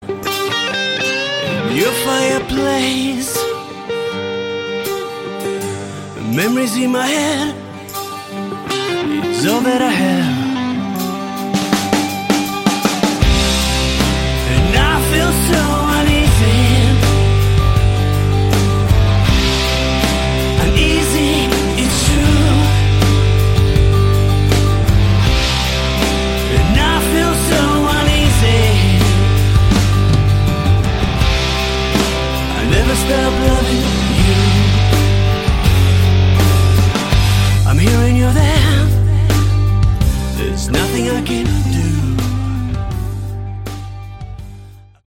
Category: Hard Rock
lead and backing vocals, guitar, piano
guitars, backing vocals
drums, percussion
bass